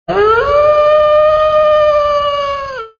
Wolf Lite.wav